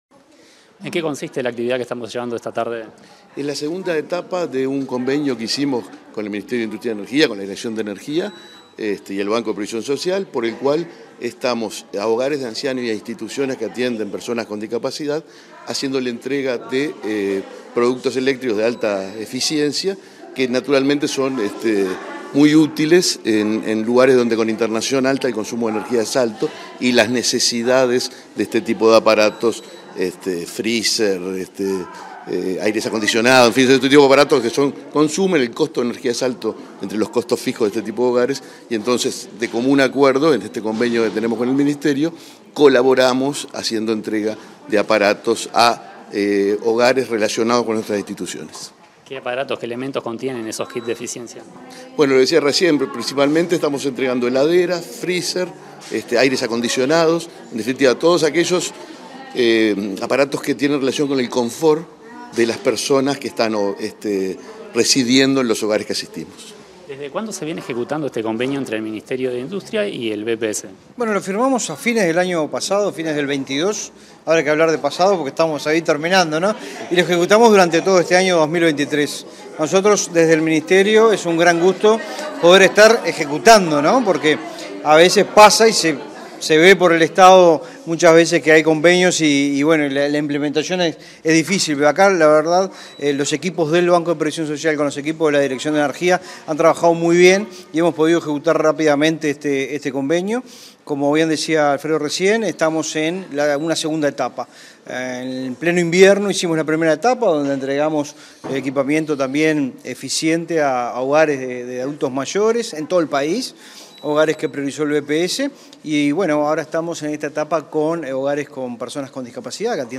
Declaraciones a la prensa en la entrega de equipamiento eléctrico eficiente
Tras la entrega de equipamiento eléctrico eficiente categoría A a hogares para personas con discapacidad inscriptos en el Registro Nacional de Instituciones del Banco de Previsión Social (BPS), este 22 de diciembre, el presidente de ese organismo, Alfredo Cabrera; el subsecretario de Industria, Fitzgerald Cantero, y la directora del BPS Daniela Barindeli, dialogaron con la prensa.